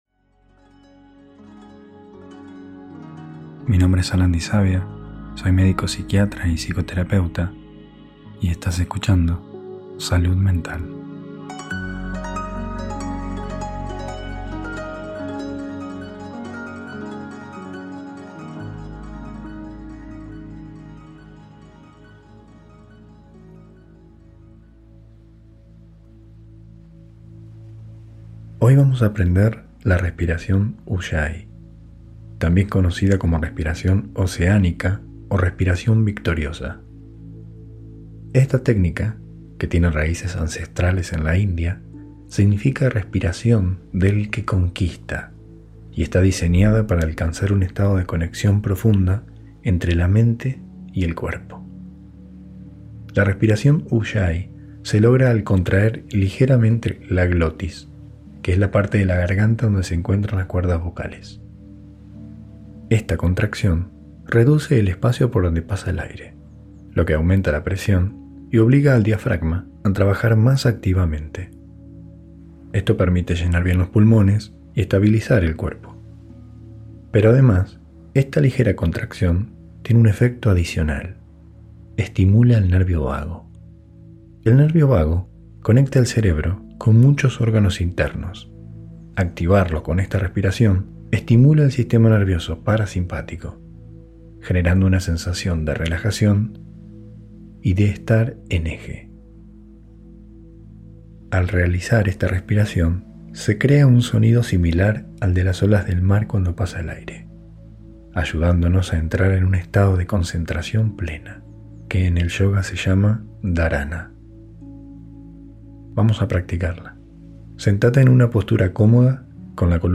Se caracteriza por un suave sonido de “olas” que se genera al pasar el aire por la garganta, creando una sensación de calma y concentración profunda. Además, Ujjayi estimula el sistema nervioso parasimpático, lo que reduce el estrés y ayuda a sentirnos más equilibrados.